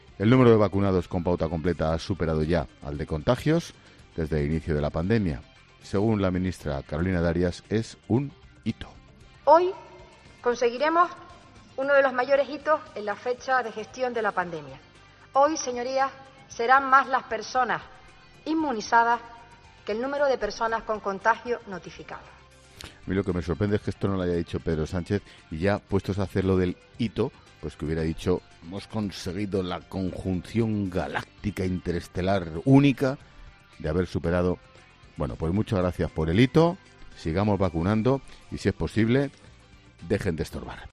El presentador de 'La Linterna' comenta el paso de la ministra de Sanidad por el Senado